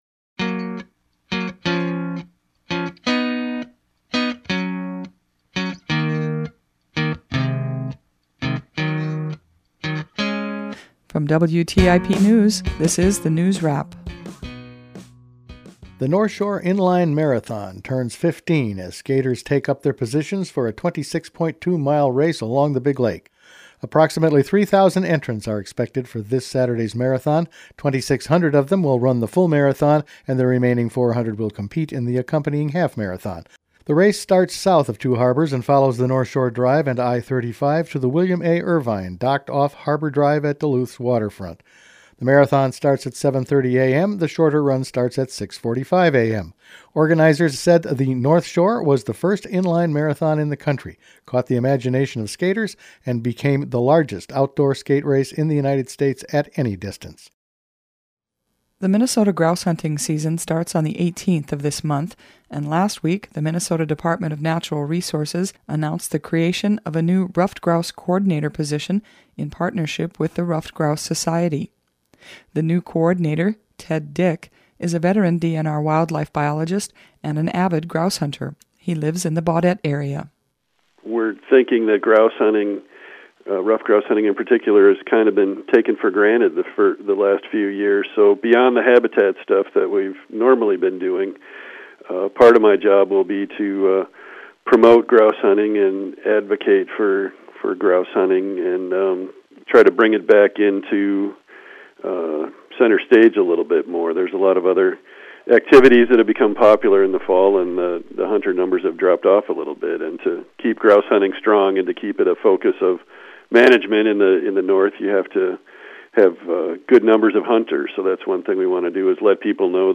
The WTIP News Department has expanded its local news coverage and now produces a News Wrap three times a week.